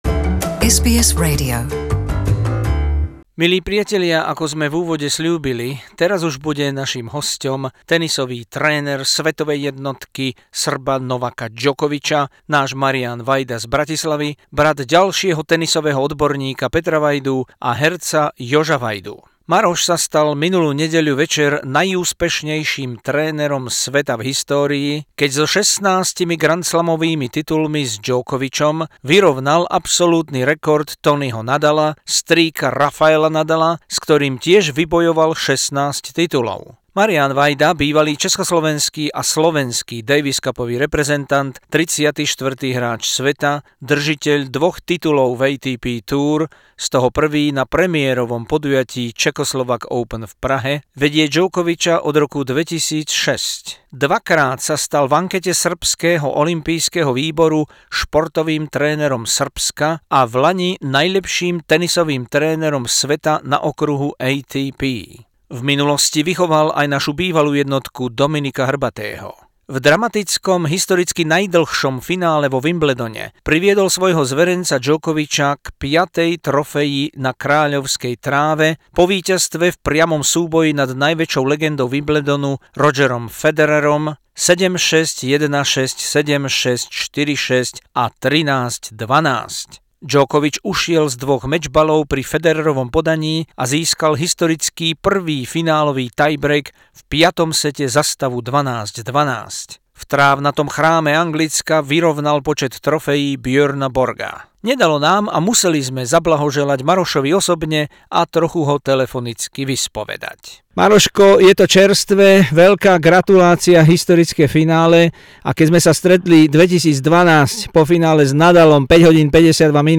Exclusive SBS only interview with the Slovak tennis coach Marian Vajda after his player Novak Djokovic claimed his 16th Grand Slam title at Wimbledon beating Roger Federer in the final from matchpoint down. Vajda is now shared best ever coach in history together with Toni Nadal, uncle of Rafa Nadal.